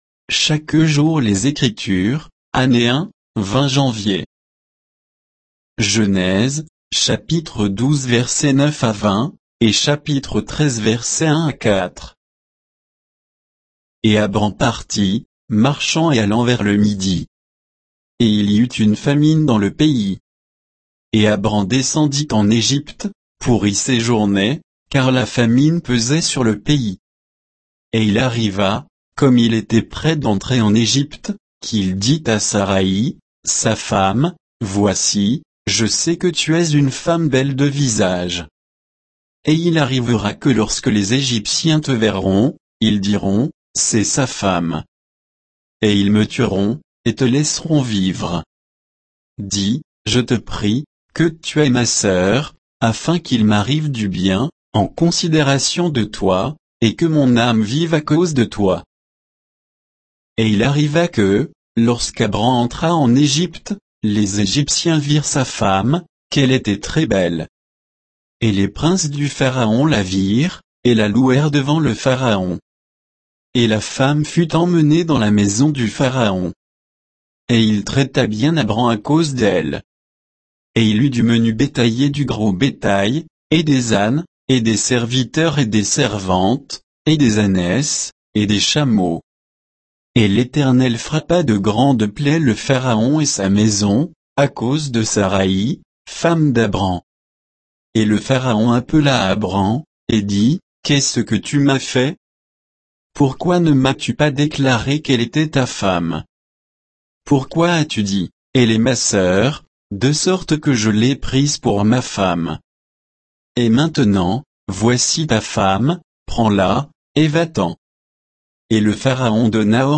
Méditation quoditienne de Chaque jour les Écritures sur Genèse 12